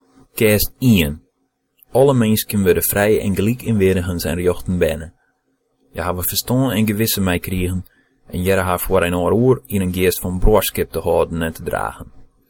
서프리슬란트어 발음